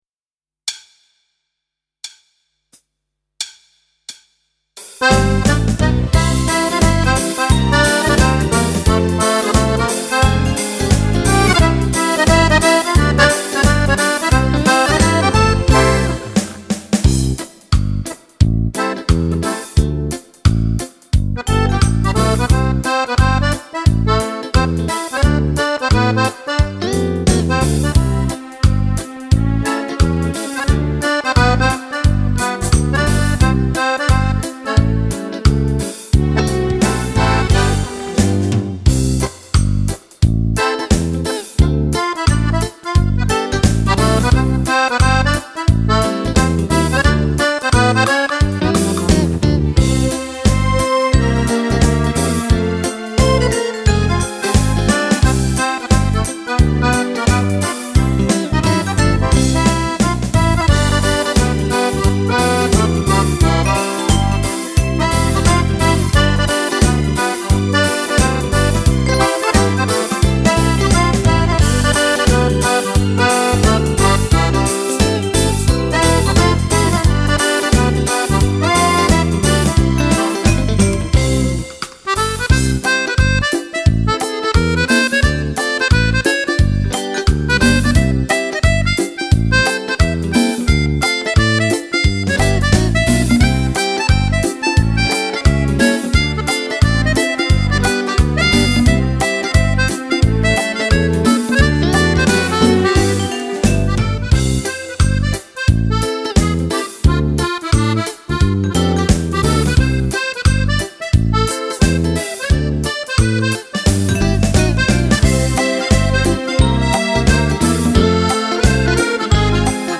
Fox country
Sax